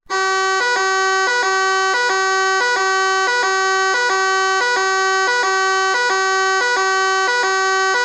AlarmeEvacuationImmmeubleCourt.mp3